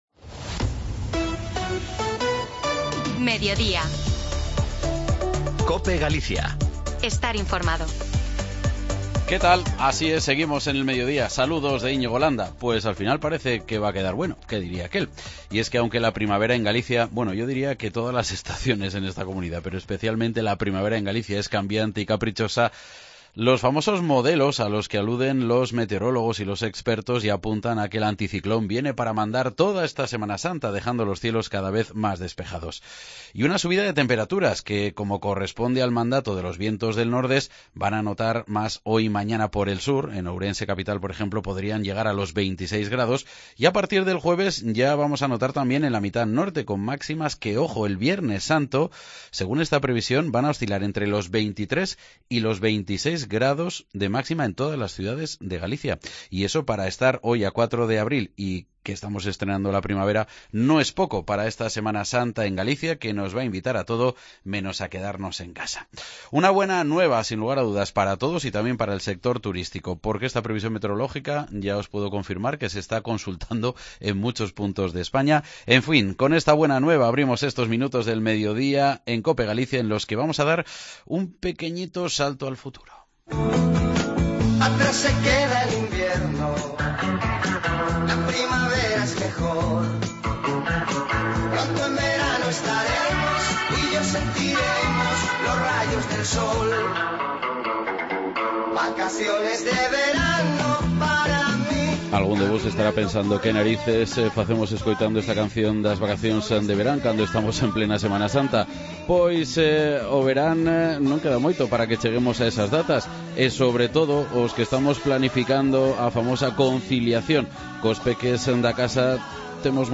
Entrevista en Mediodía Cope Galicia con la directora xeral de Xuventude, Cristina Pichel